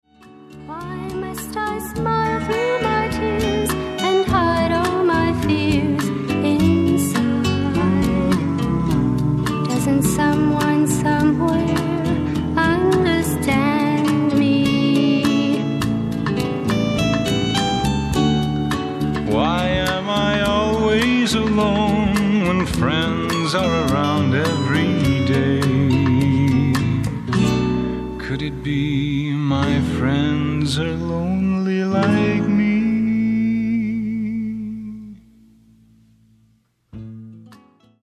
SOFT ROCK / GARAGE